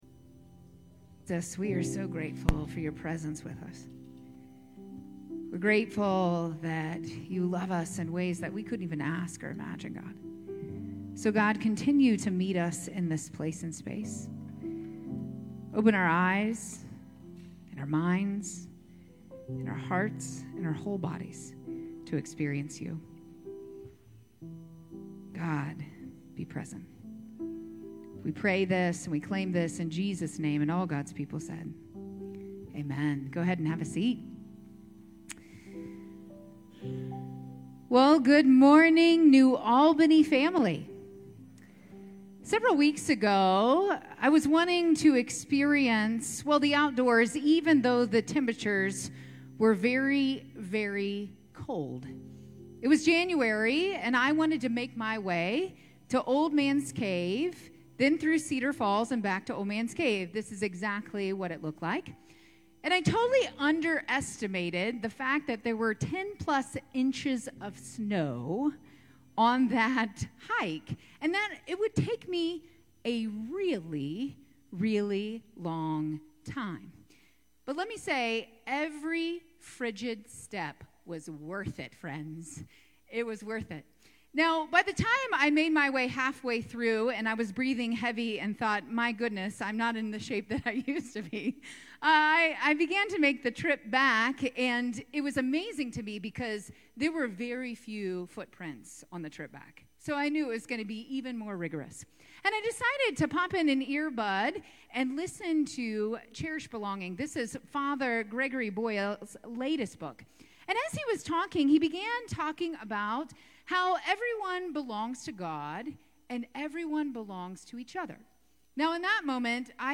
9:30 Redemption Worship Service 03/16/25